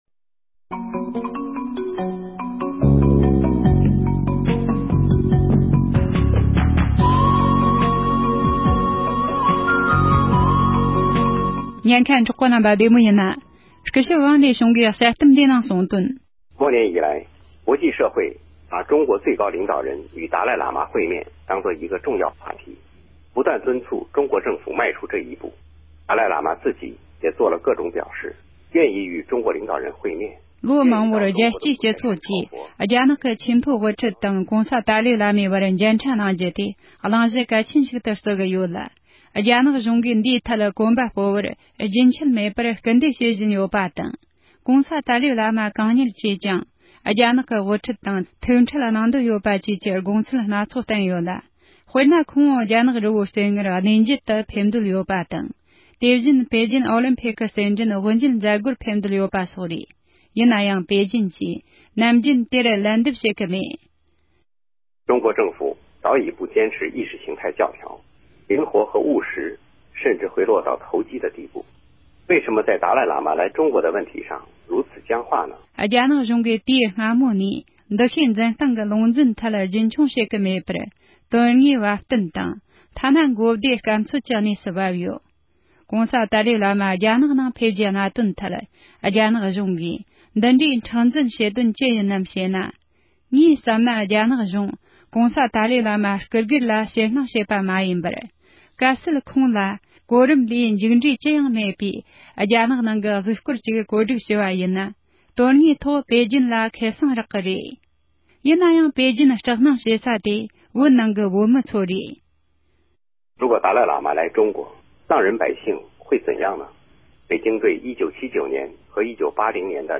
ཡིག་བསྒྱུར་དང་རྒྱང་བསྲིངས་ཞུས་པ་ཞིག་གསན་རོགས་གནང་།